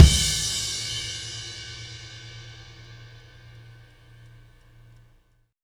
Indie Pop Beat Ending 01.wav